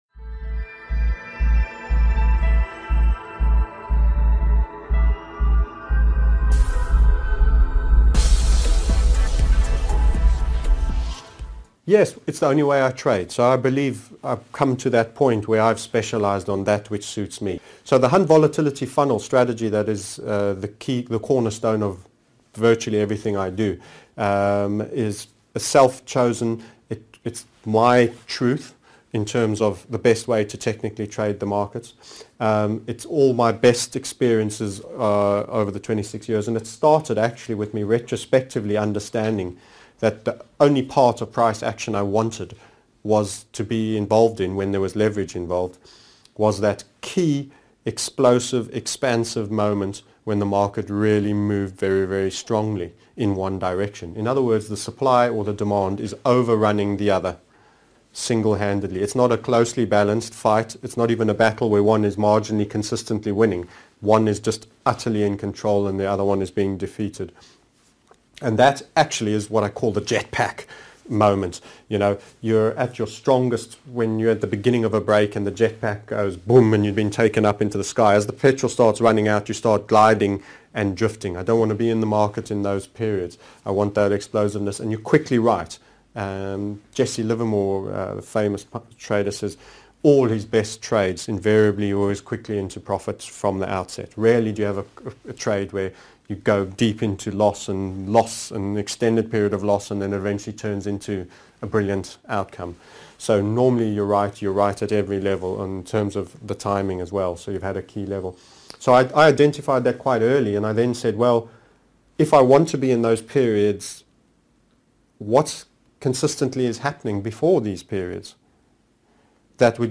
12 What is the Hunt Volatility Funnel TMS Interviewed Series 12 of 32